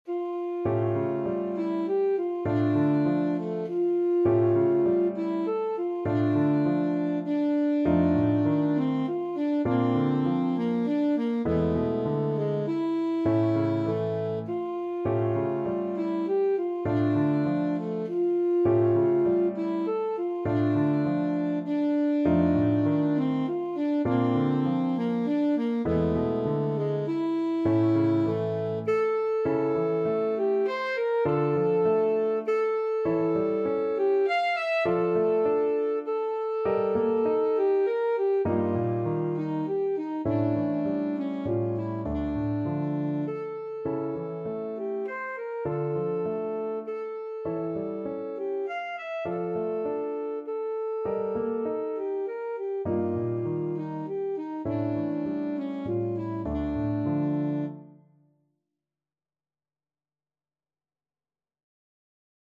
Alto Saxophone
D minor (Sounding Pitch) B minor (Alto Saxophone in Eb) (View more D minor Music for Saxophone )
3/4 (View more 3/4 Music)
Etwas bewegt
Classical (View more Classical Saxophone Music)
fuchs_op47_5_ASAX.mp3